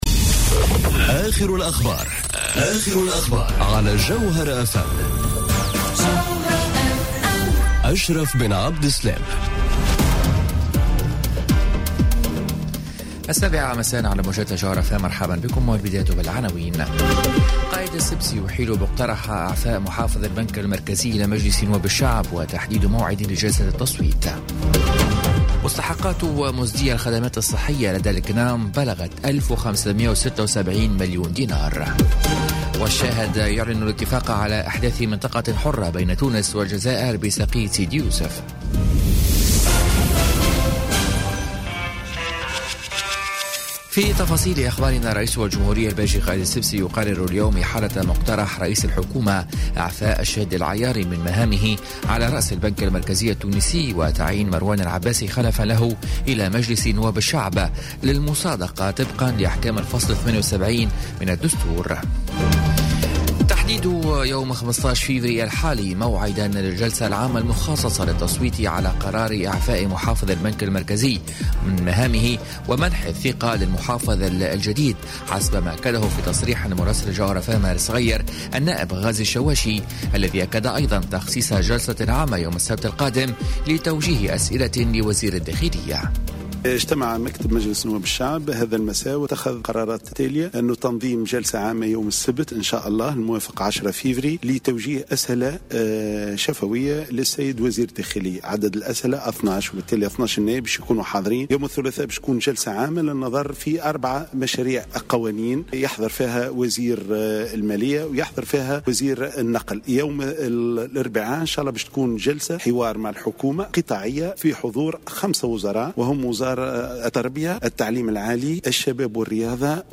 نشرة أخبار السابعة مساءً ليوم الخميس 8 فيفري 2018